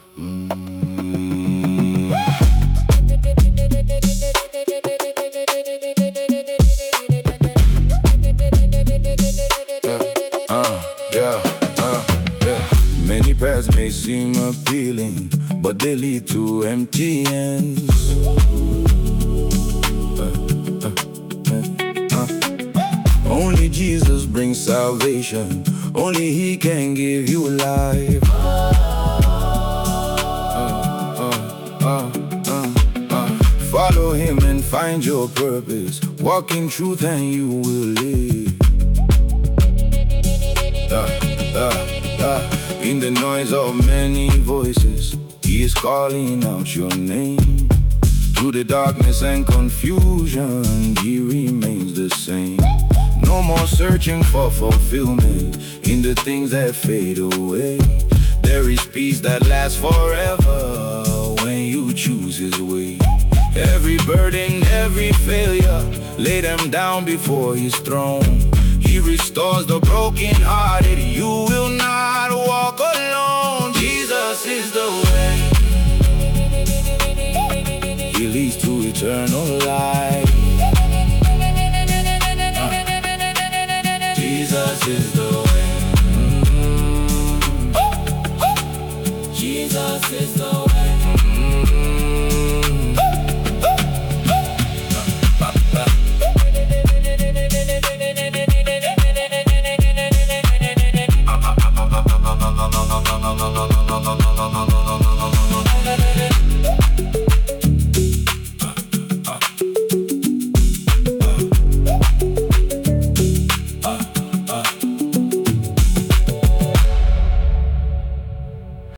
Nigerian gospel artist